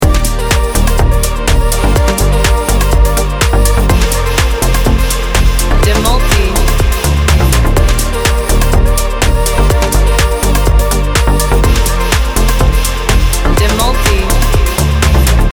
Dengan pola beat yang mengejutkan